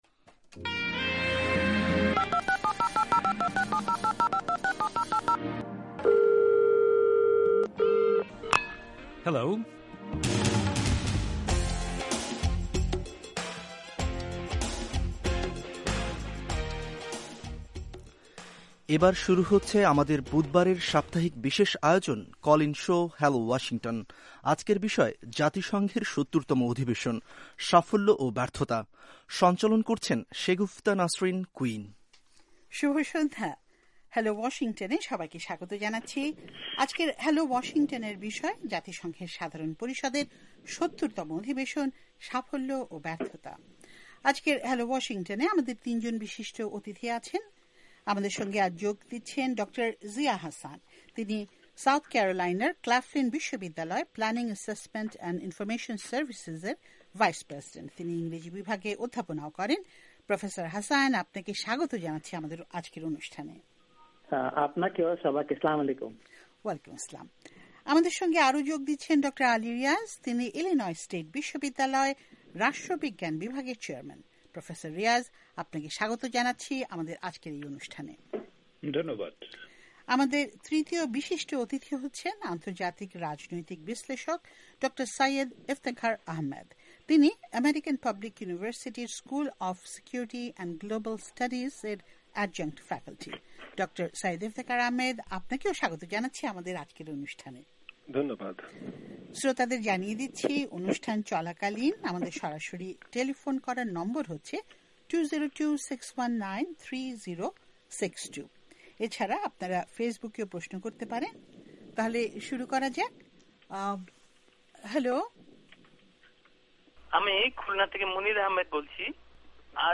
আজকের হ্যালো ওয়াশিংটনে আমাদের তিনজন বিশেষ অতিথি ছিলেন।